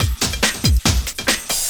04 LOOP08 -L.wav